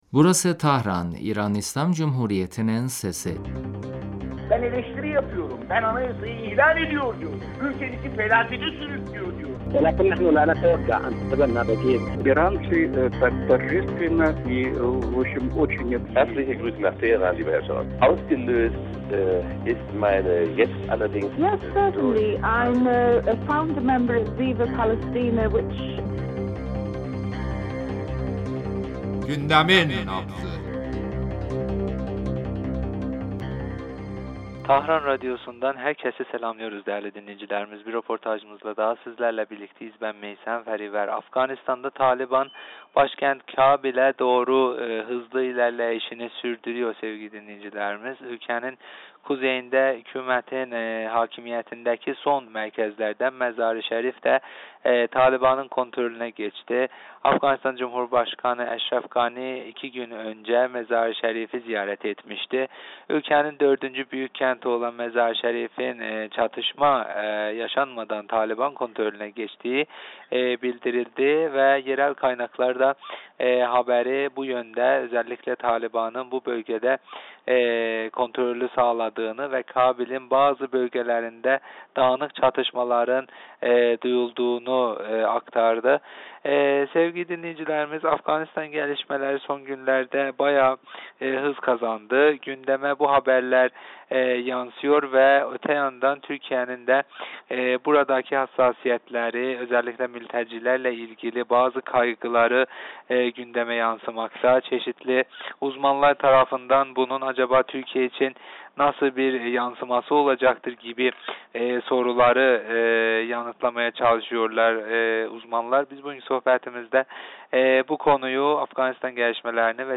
Siyasi analist sn.